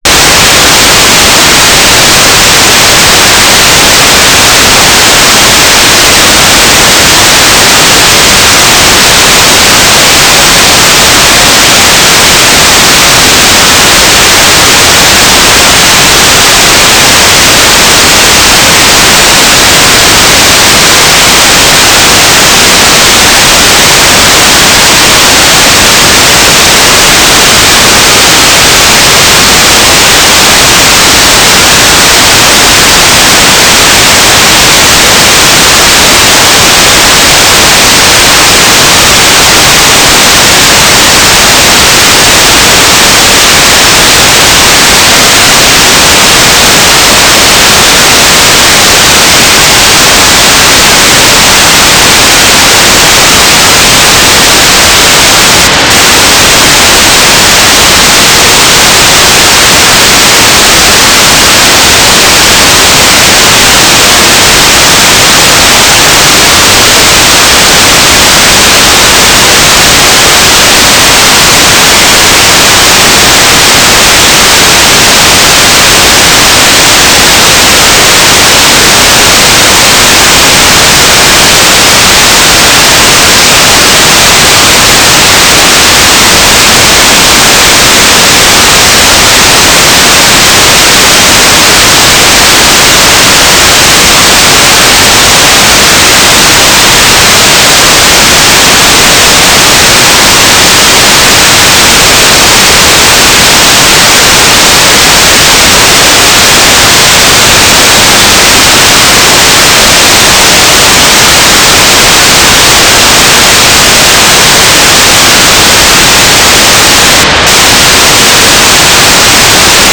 "transmitter_description": "Telemetry",
"transmitter_mode": "FSK",